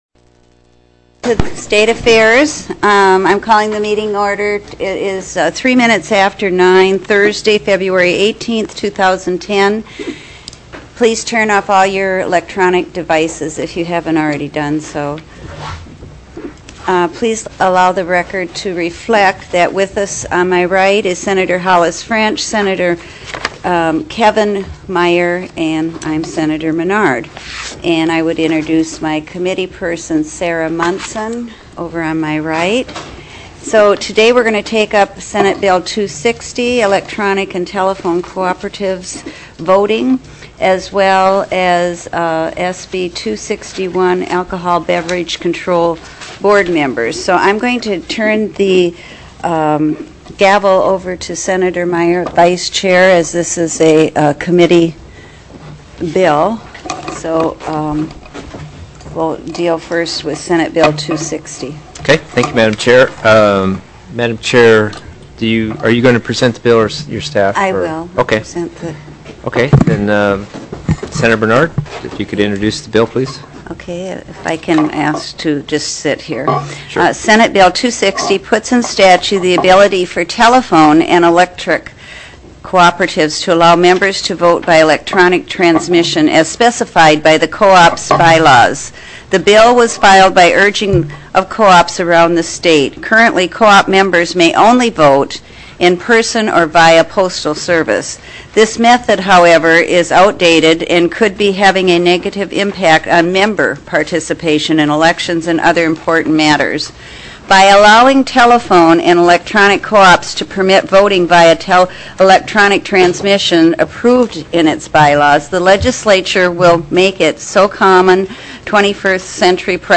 02/18/2010 09:00 AM Senate STATE AFFAIRS
SB 260 ELECTRIC & TELEPHONE COOPERATIVES' VOTING TELECONFERENCED